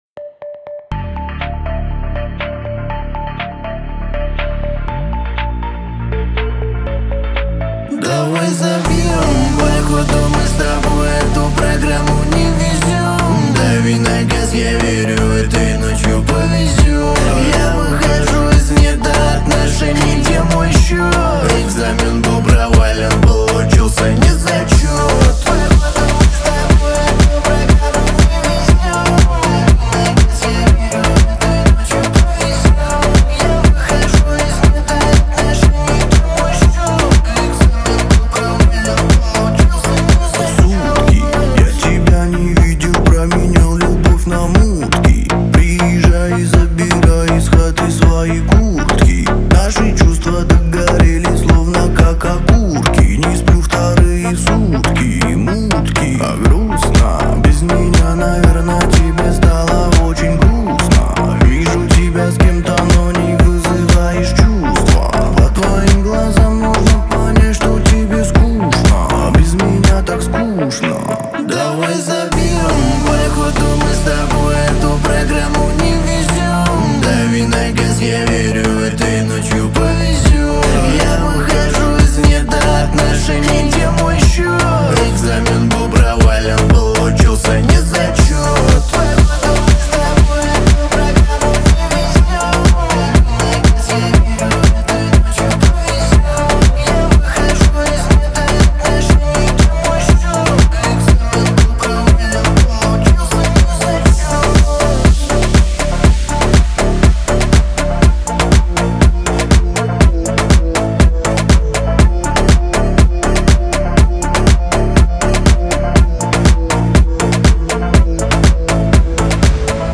Новинки музыки в пятницу, Рэп